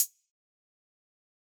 PluGG hi hat (2).wav